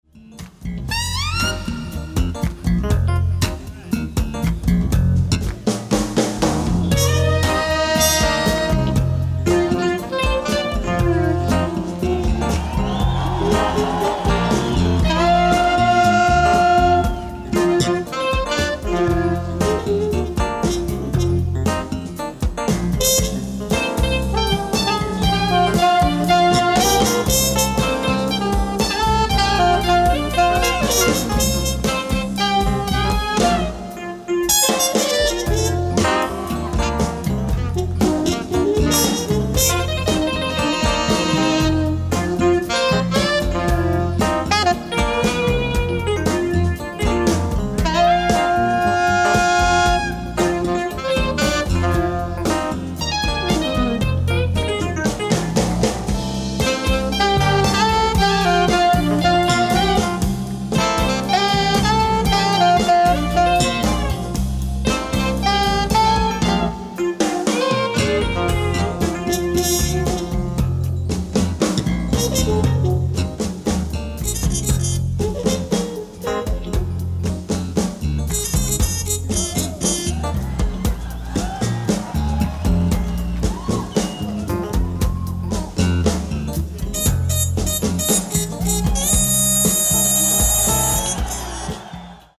ライブ・アット・ヴィレット・グランドホール、パリ、フランス 07/10/1991
※試聴用に実際より音質を落としています。